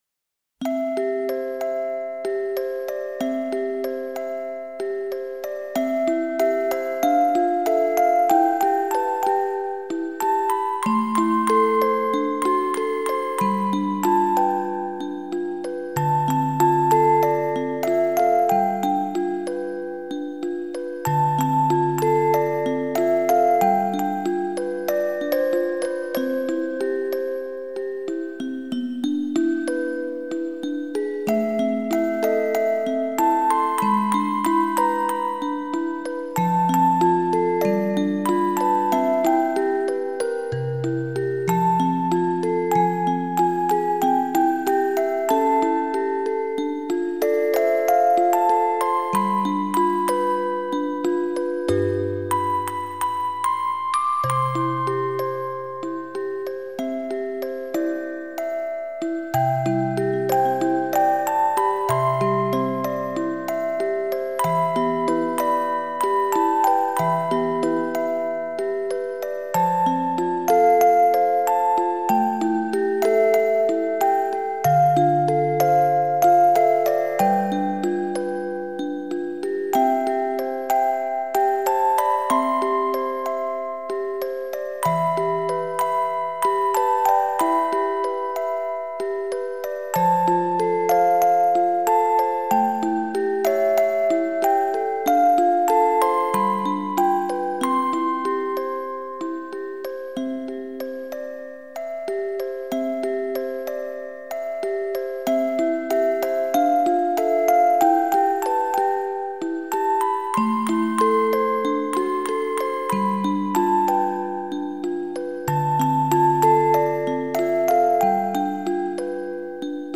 ♪イメージソング（オルゴール）
3.オルゴールＢＧ